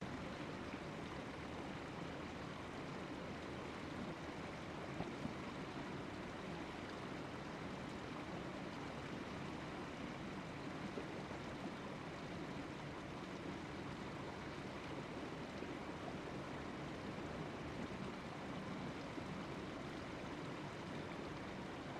Шепот тишины у опустевшего бассейна